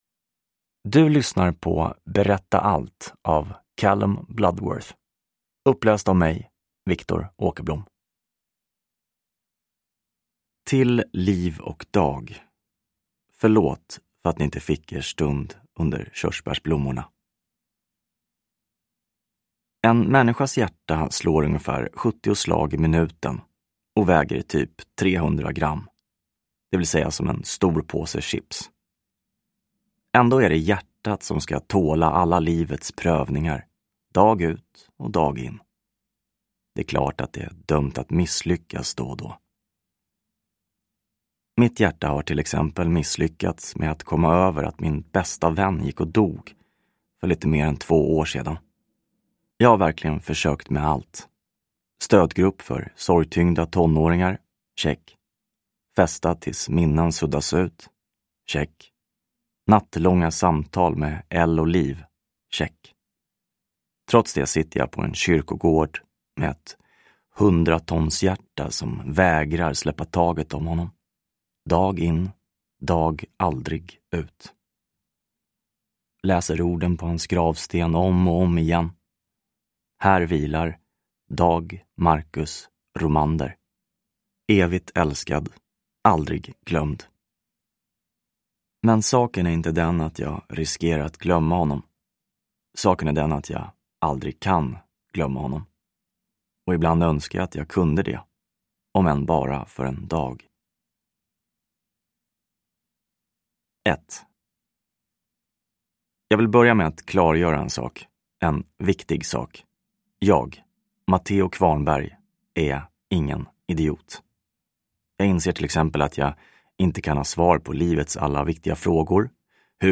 Berätta allt (ljudbok) av Callum Bloodworth | Bokon